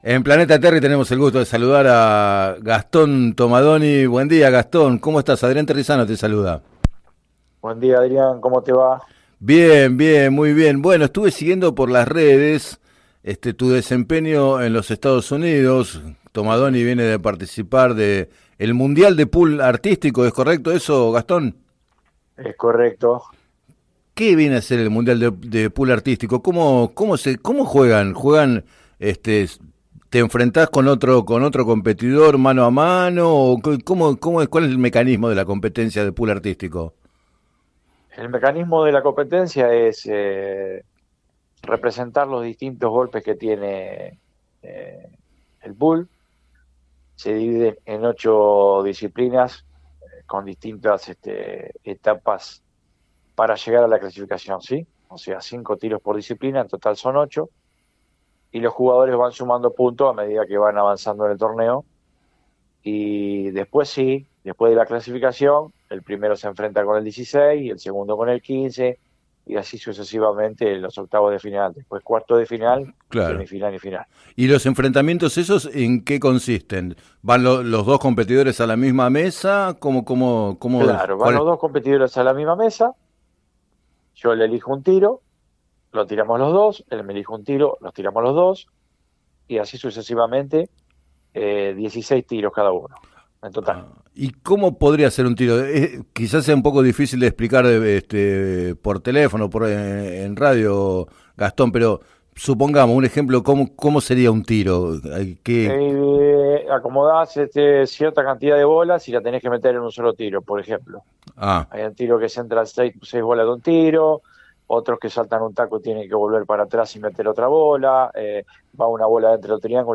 En el programa Planeta Terri contó que perdió en cuartos de final porque lo traicionaron los nervios.